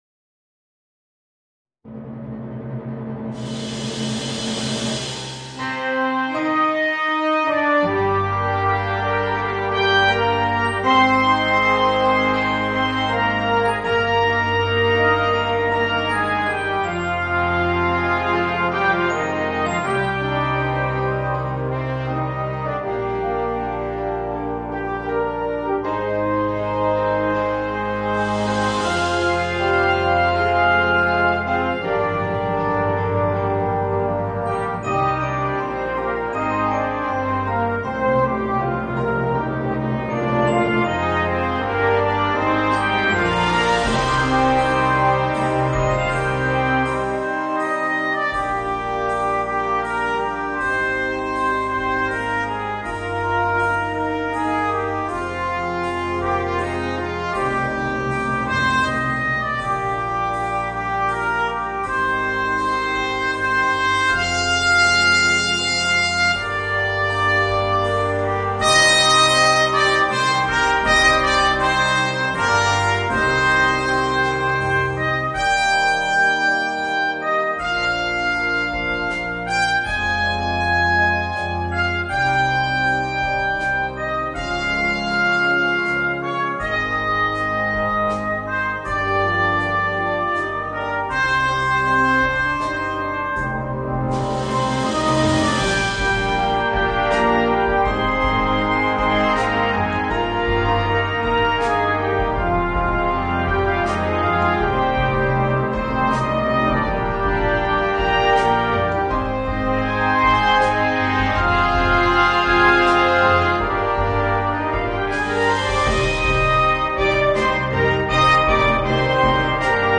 Voicing: Violoncello and Brass Band